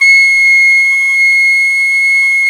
FLT PICC2C1A.wav